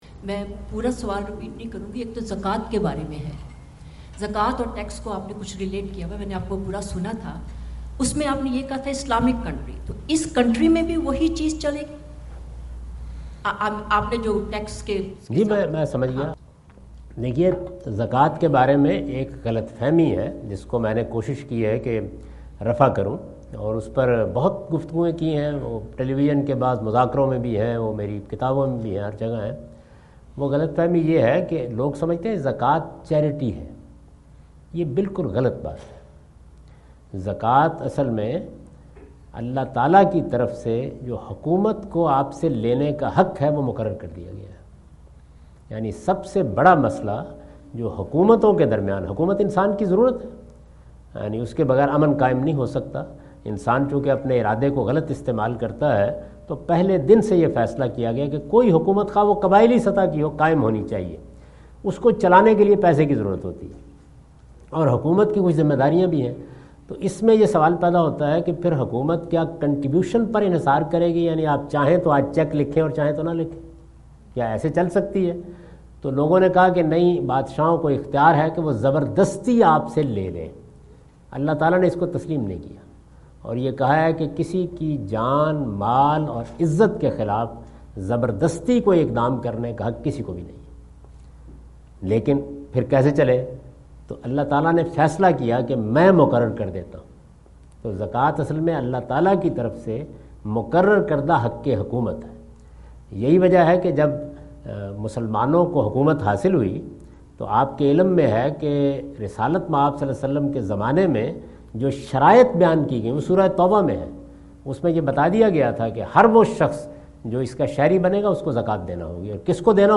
Javed Ahmad Ghamidi answer the question about "Zakah and Tax Laws for Non-Muslim States" During his US visit in Dallas on October 08,2017.
جاوید احمد غامدی اپنے دورہ امریکہ2017 کے دوران ڈیلس میں "غیر مسلم ممالک کے زکات اور ٹیکس کے قوانین" سے متعلق ایک سوال کا جواب دے رہے ہیں۔